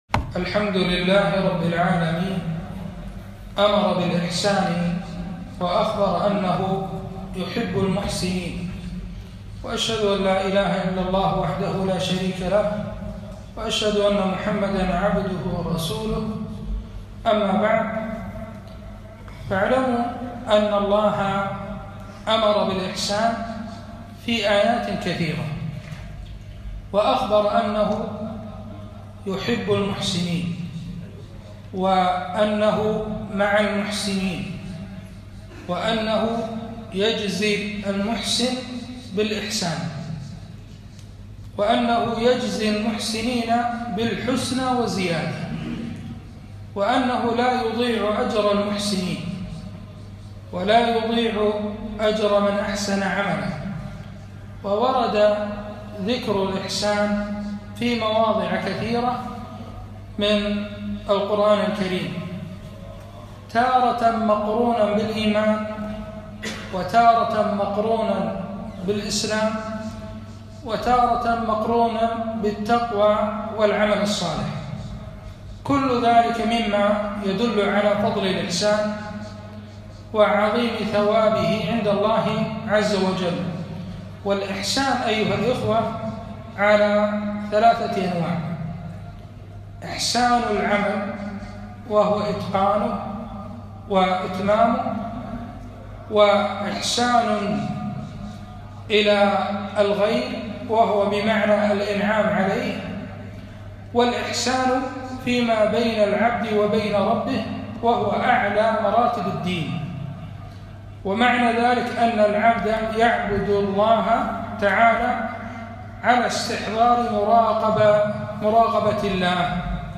محاضرة - الإحسان في دين الإسلام